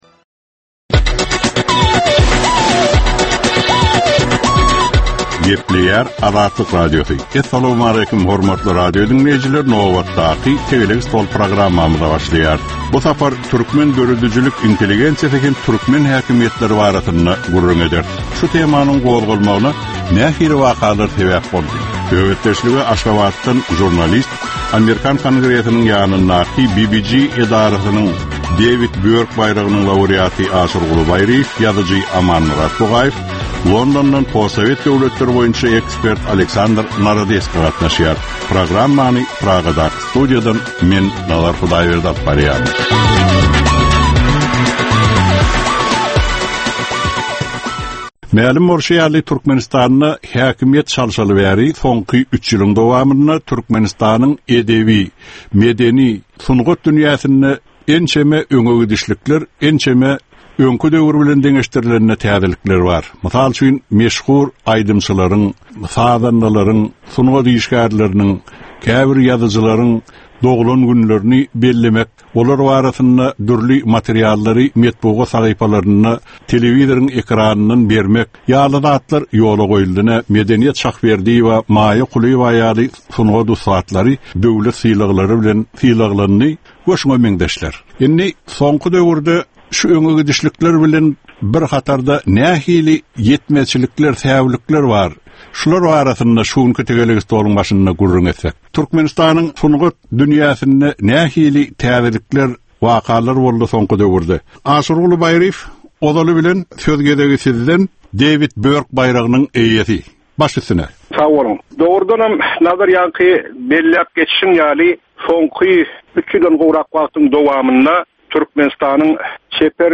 Jemgyýetçilik durmuşynda bolan ýa-da bolup duran soňky möhum wakalara ýa-da problemalara bagyşlanylyp taýýarlanylýan ýörite “Tegelek stol” diskussiýasy. Bu gepleşikde syýasatçylar, analitikler we synçylar anyk meseleler boýunça öz garaýyşlaryny we tekliplerini orta atýarlar.